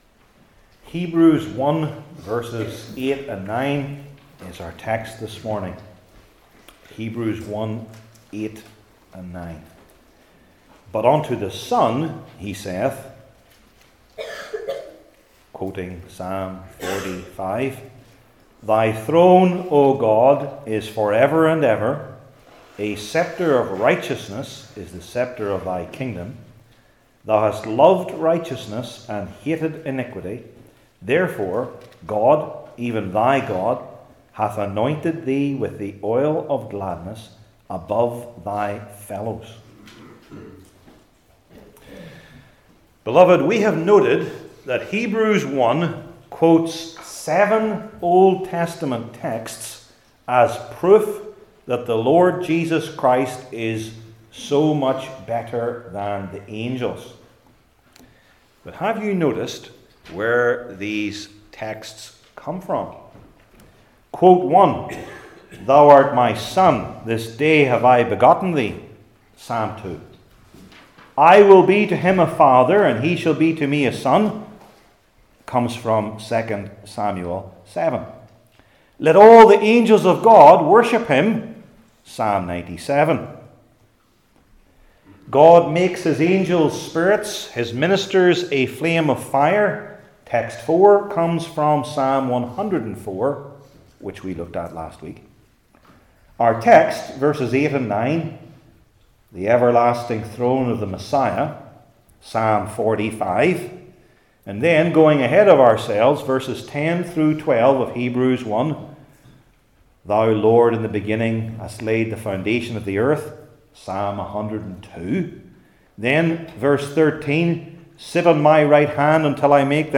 New Testament Sermon Series I. The One Addressed II.